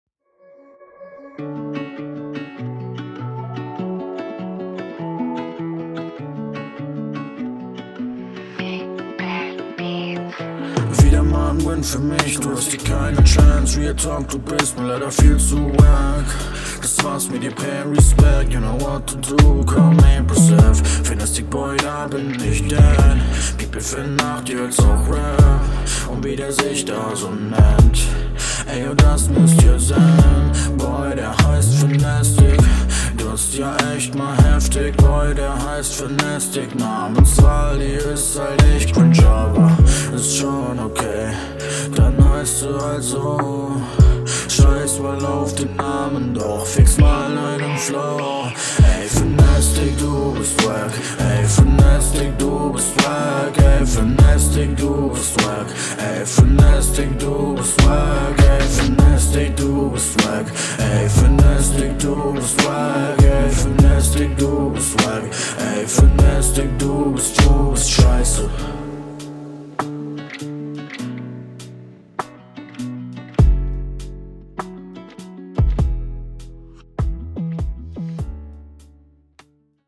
Battle Rap Bunker
Text komplett nichts-sagend und Sound kommt auch nicht so geil mit dem Effekt.